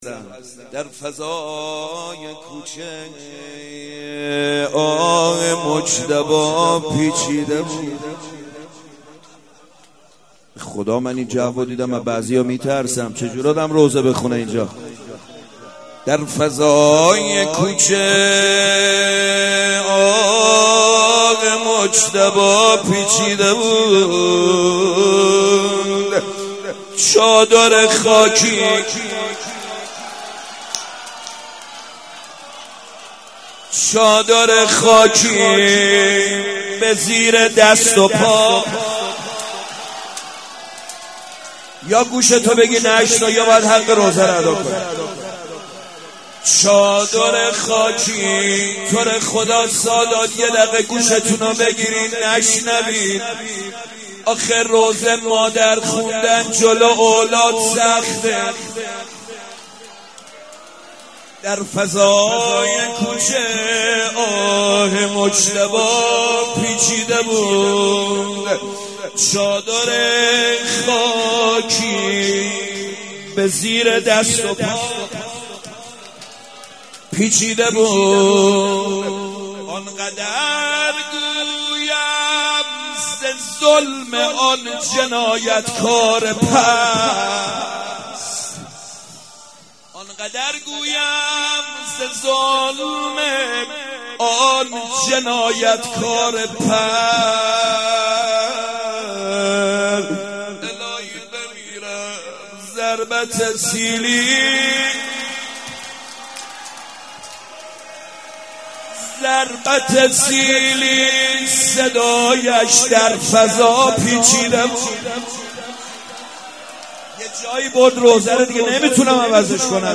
روضه امام حسن